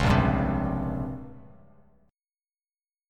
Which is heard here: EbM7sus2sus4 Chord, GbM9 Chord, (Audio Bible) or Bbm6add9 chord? Bbm6add9 chord